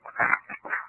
Common EVP Phrases
Are Phrases We Often Hear When Recording EVP